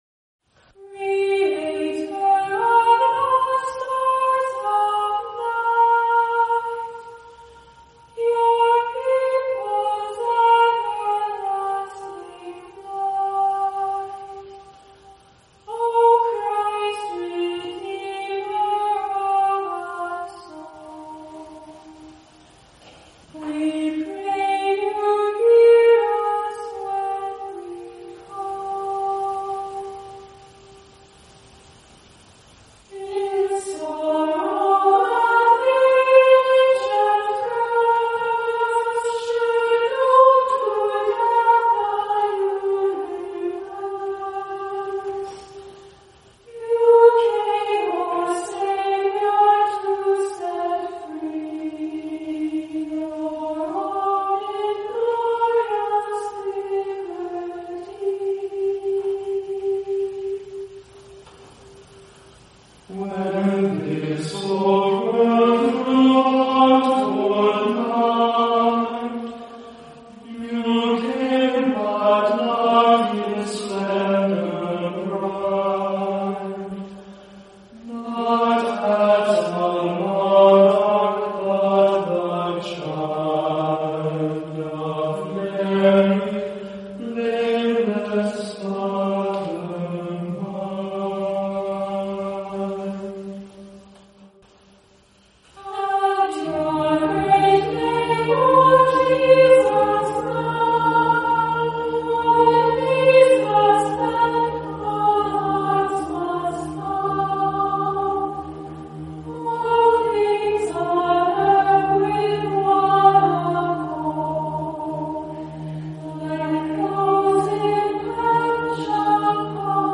“Creator of the Stars of Night” Performed by St. John’s Episcopal Church Compline Choir in Boulder
conductor.